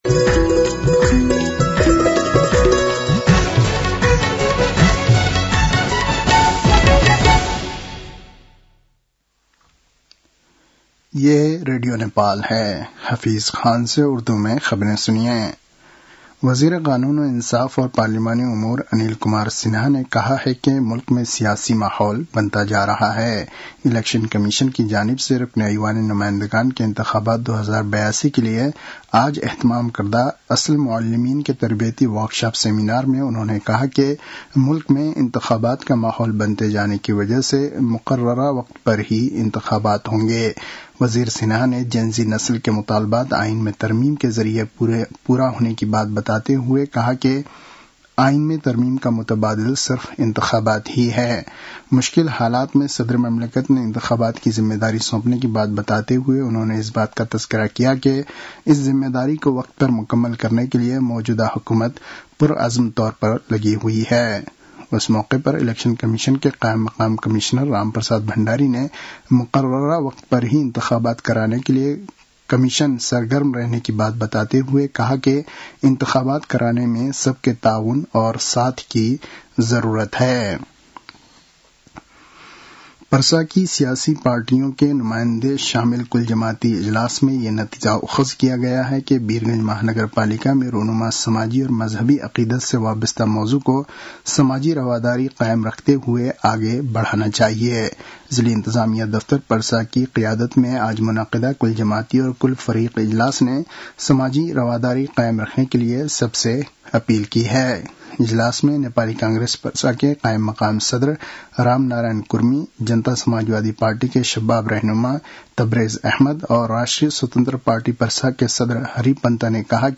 उर्दु भाषामा समाचार : २२ पुष , २०८२